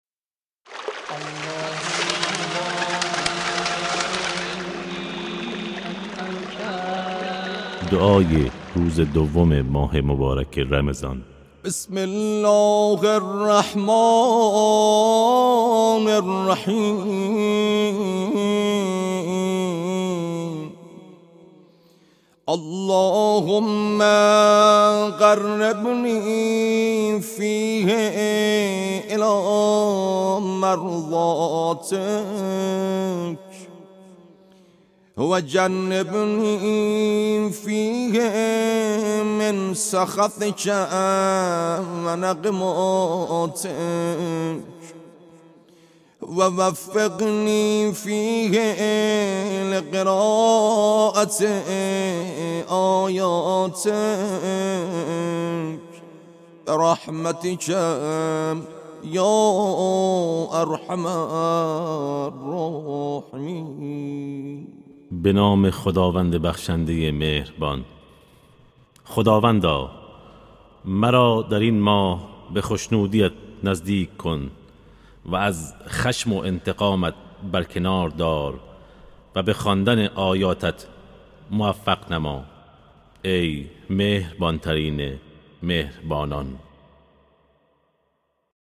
دعای روزهای ماه مبارک رمضان